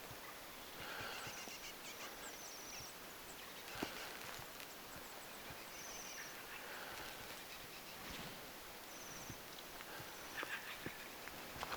vähän erikoista sinitiaisen ääntelyä
sinitiaisen_tuollaista_aantelya.mp3